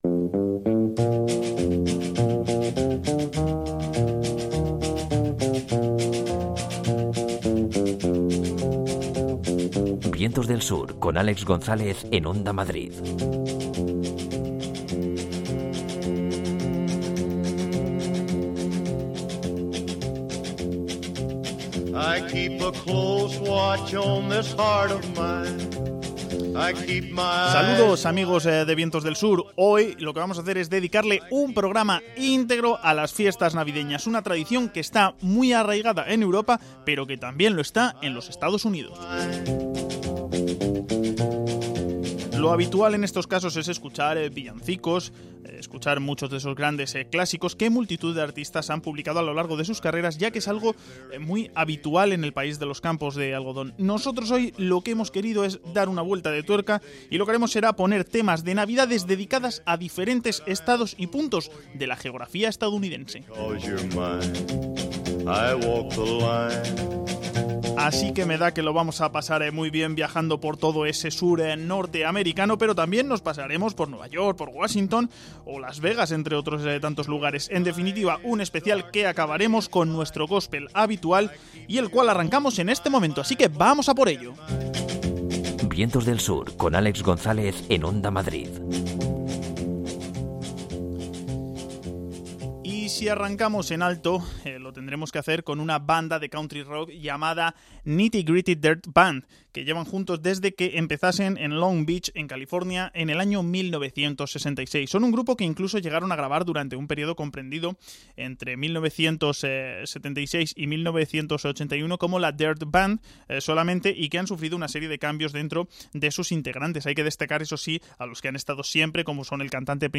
Además, el grupo de góspel final es The Churchmen.